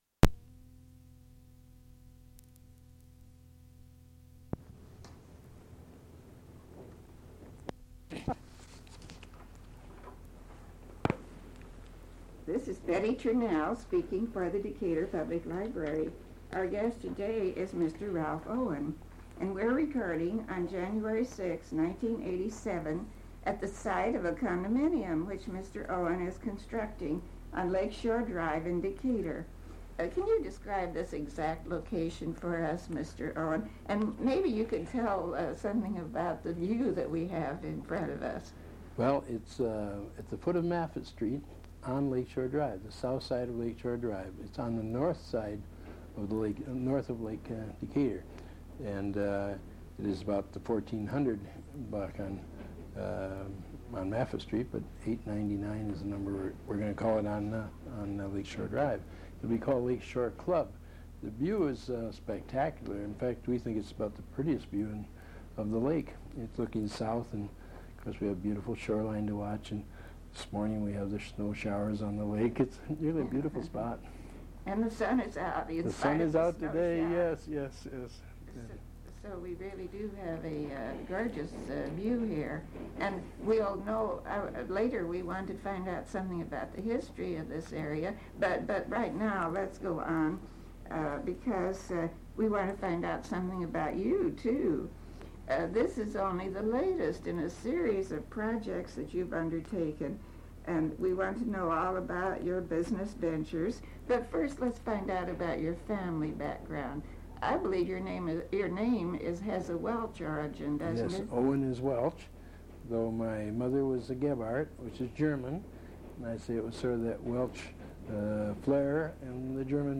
interview
oral history